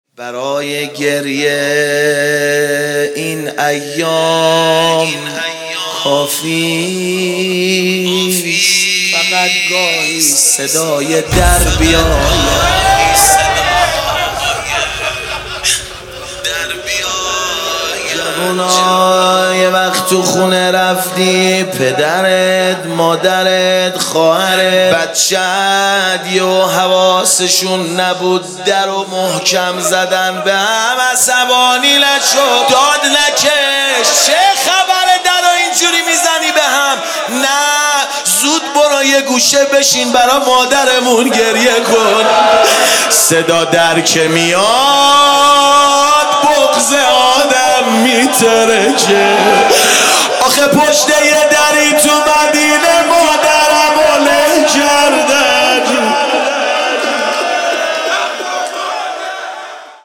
ایام فاطمیه 1441